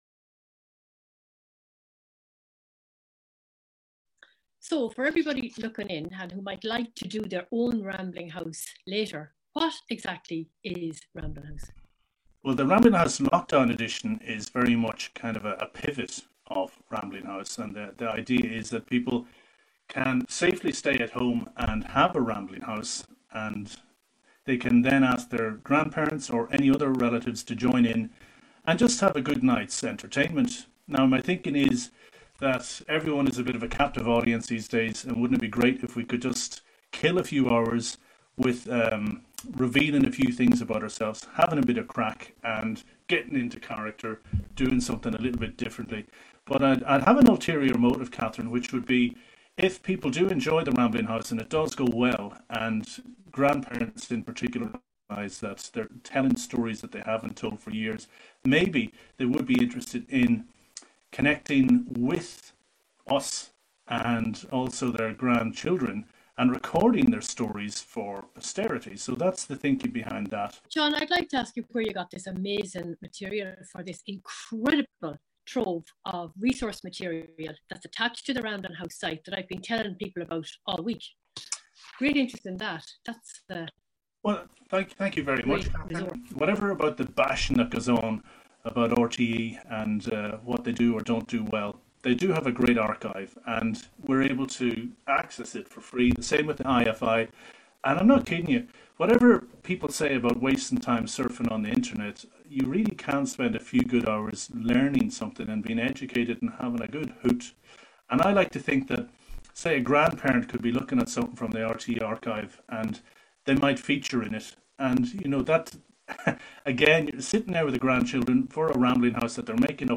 Rambling House project brief Q&A